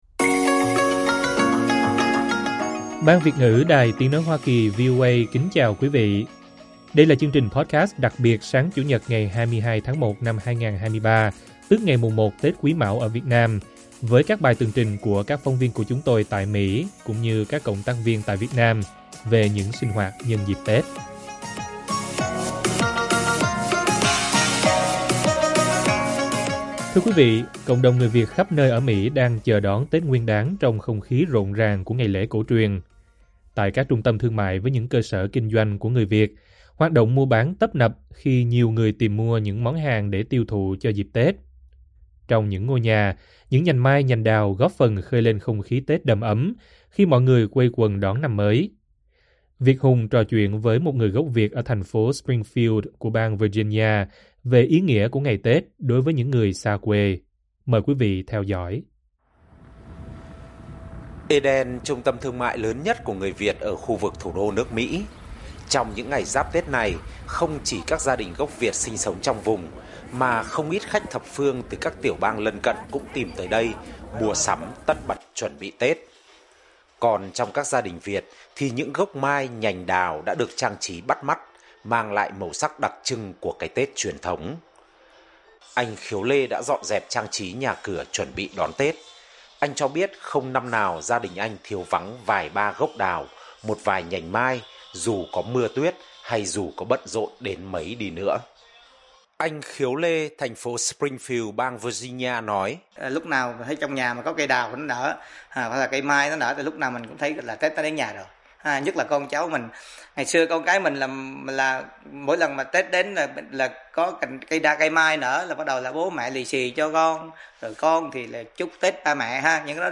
Chương trình podcast đặc biệt sáng Chủ nhật ngày 22 tháng 1 năm 2023, tức ngày mùng 1 Tết Quý Mão, ở Việt Nam với các bài tường trình của các phóng viên của chúng tôi tại Mỹ cũng như các cộng tác viên tại Việt Nam về những sinh hoạt nhân dịp Tết.